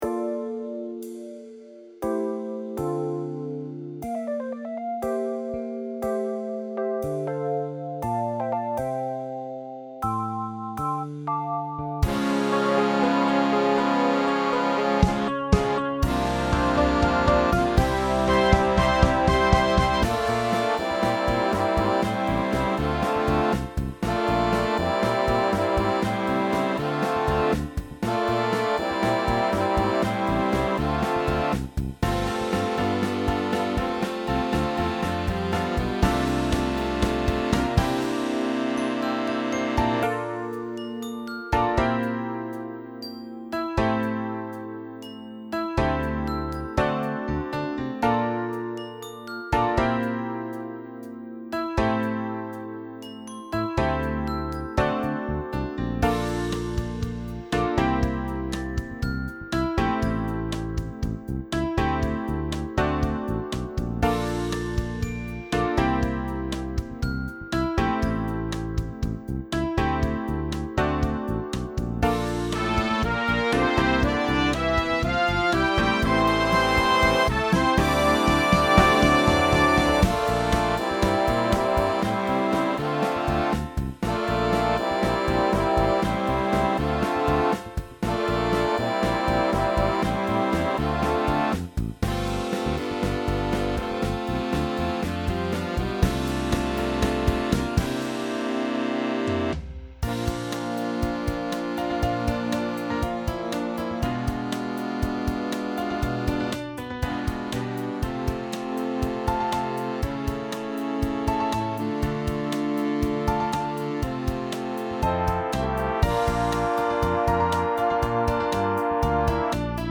Motown classic.
Scored for full big band.